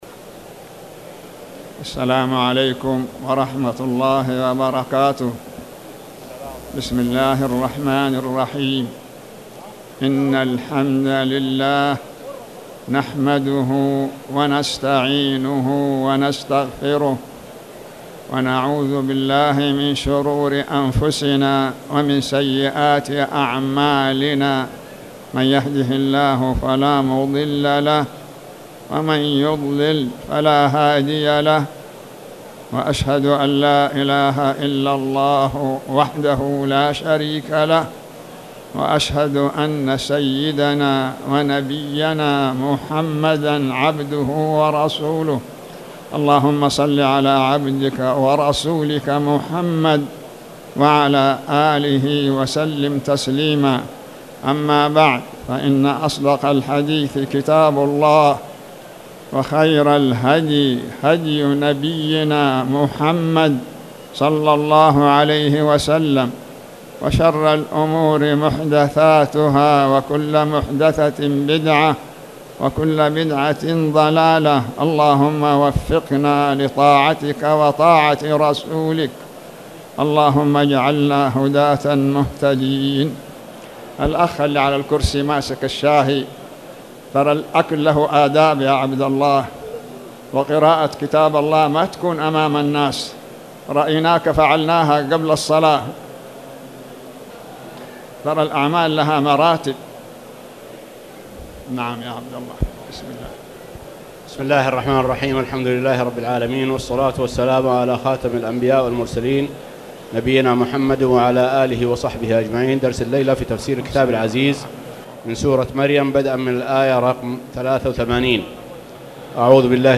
تاريخ النشر ٩ جمادى الأولى ١٤٣٨ هـ المكان: المسجد الحرام الشيخ